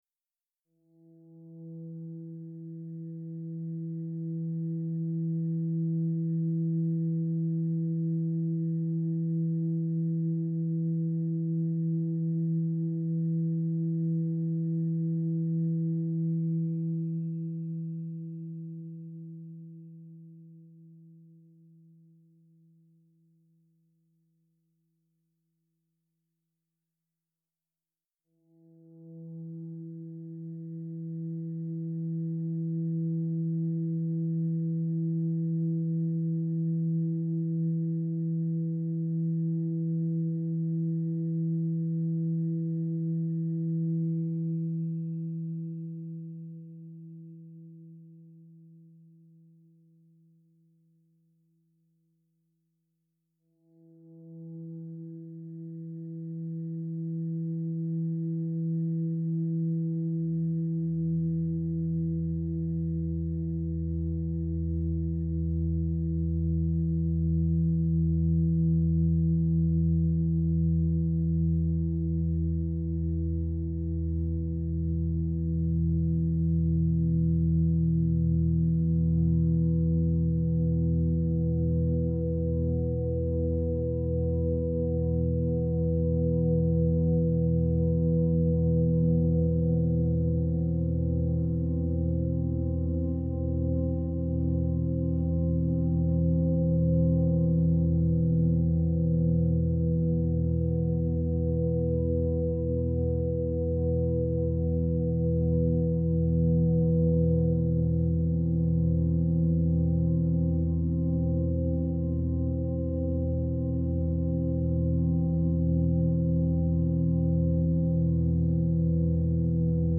deep ambient
using a blend of synthesized sounds and field recordings.